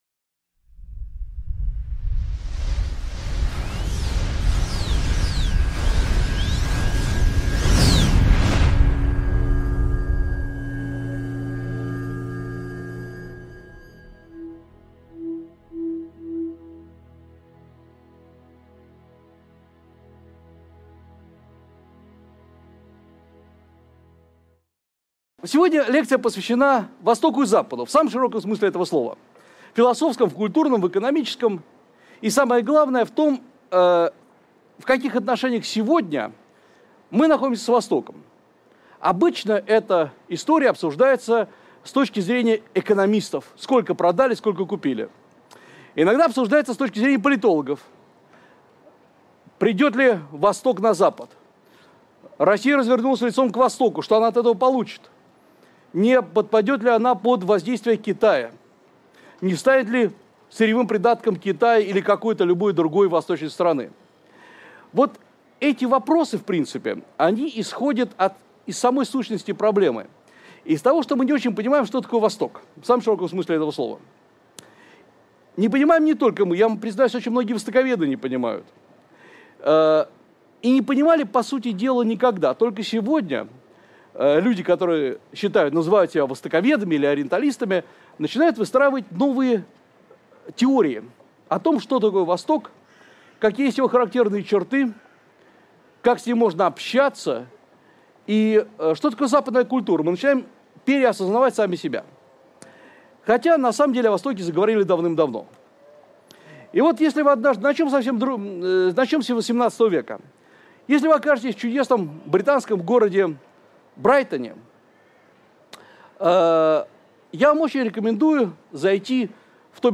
Аудиокнига Когда Восток придет на Запад: тенденции политики – судьбы культуры | Библиотека аудиокниг